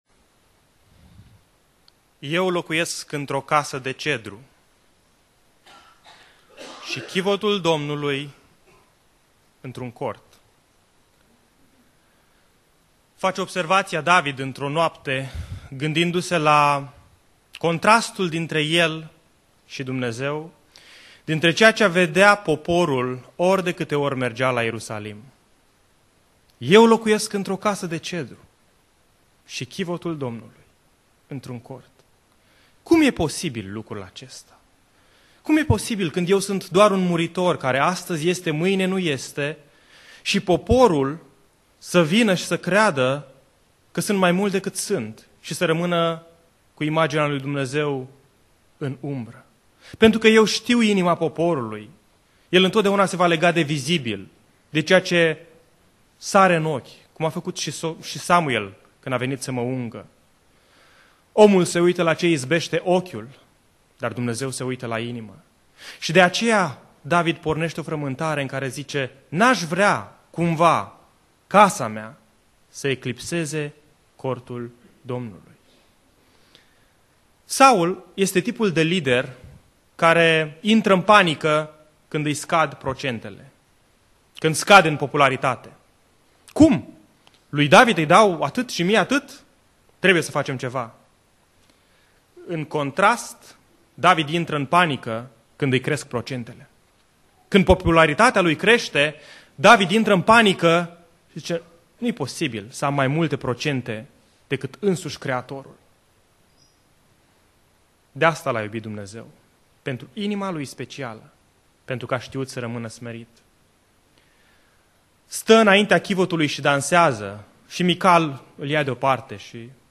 Predica Aplicatie - 1 Imparati Cap 5-7